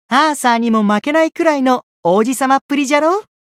觉醒语音 アーサーにも負けないくらいの王子様っぷりじゃろう？